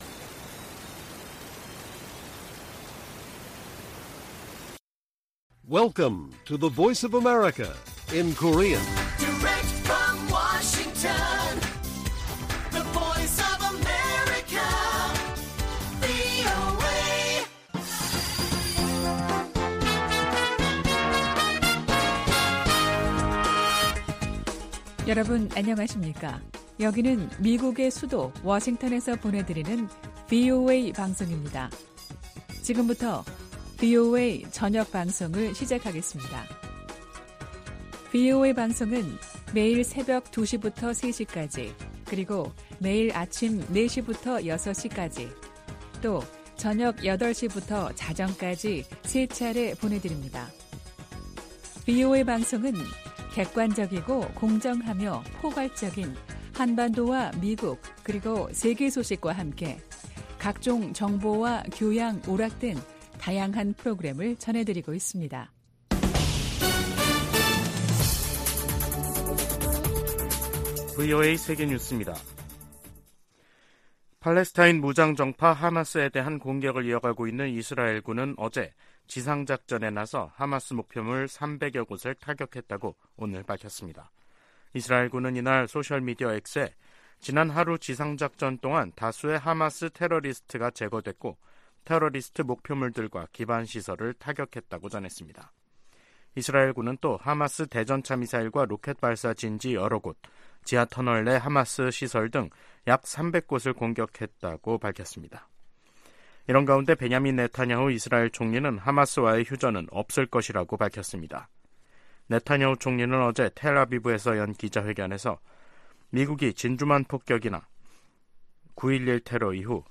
VOA 한국어 간판 뉴스 프로그램 '뉴스 투데이', 2023년 10월 31일 1부 방송입니다. 미 국무부 대북특별대표가 중국 한반도사무 특별대표와 화상회담하고 북러 무기거래가 비확산 체제를 약화시킨다고 지적했습니다. 하마스가 북한제 무기를 사용했다는 정황이 나온 가운데 미 하원 외교위원장은 중국·이란에 책임을 물어야 한다고 주장했습니다. 북한의 핵 위협에 대한 한국 보호에 미국 핵무기가 사용될 것을 확실히 하는 정책 변화가 필요하다는 보고서가 나왔습니다.